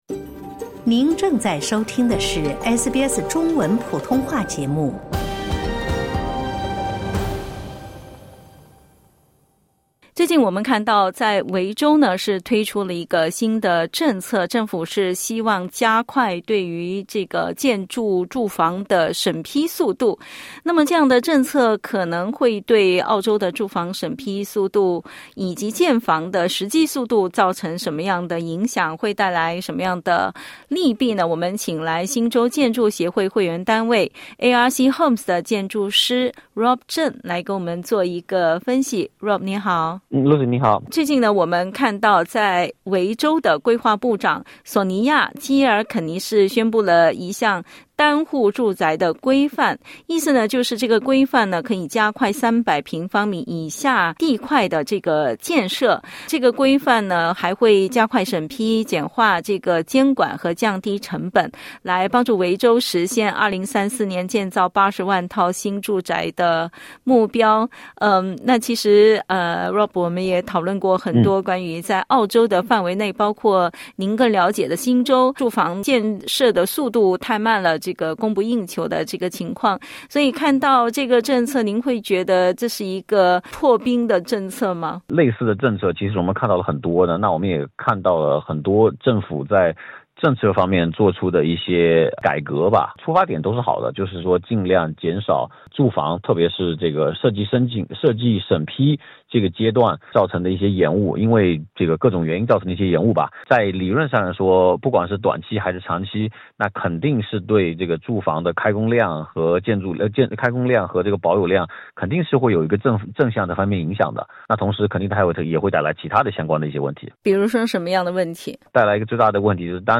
（点击音频收听详细采访） 近日，维多利亚州规划部长索尼娅·基尔肯尼(Sonya Kilkenny )宣布了一项“单户住宅规范”（single house code），她表示，该规范将加快300平方米以下小地块的建设。